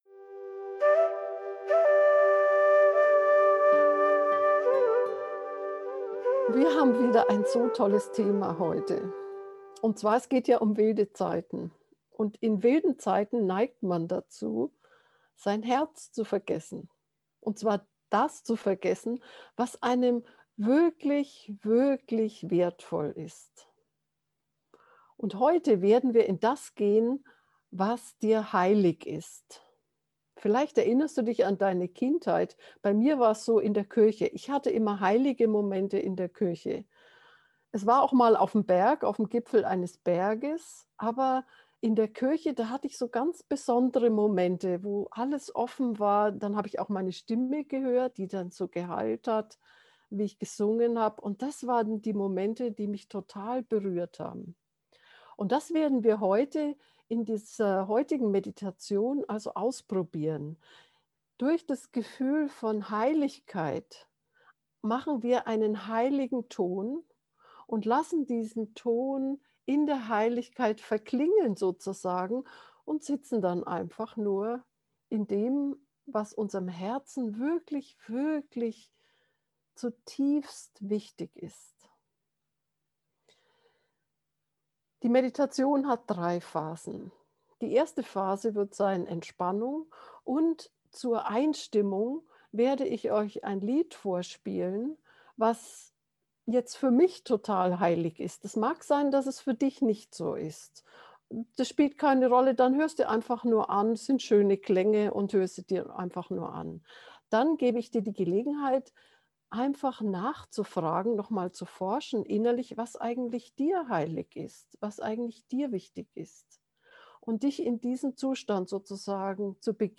Mit dieser geführten Meditation aus dem Buch der Geheimnisse folgen wir einem heiligen Ton, der immer leiser und leiser wird, bis der heilige Klang nur noch im Herzen vibriert. Von dort dehnt er sich im ganzen Körper und Sein aus.
heiliger-raum-gefuehrte-herzmeditation